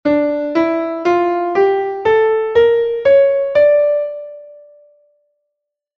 Escalas menores